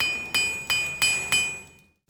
anvil_use.ogg